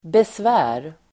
Uttal: [besv'ä:r]